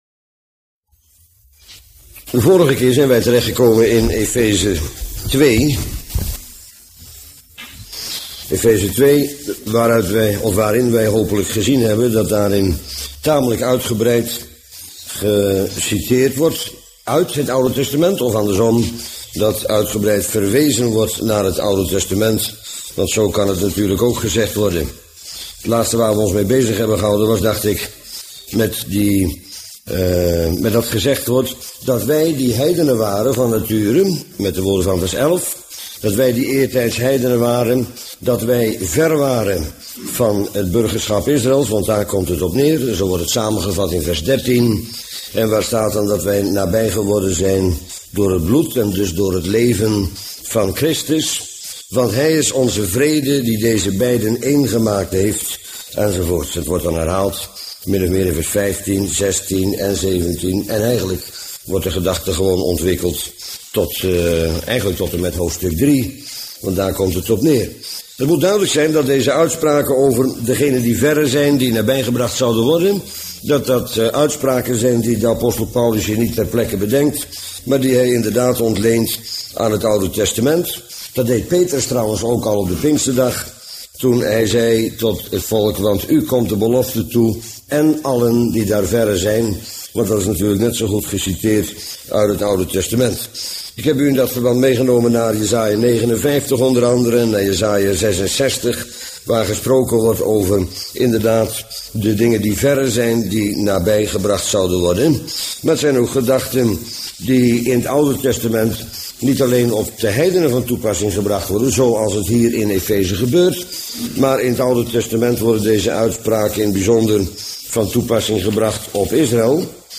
Bijbelstudie lezingen mp3.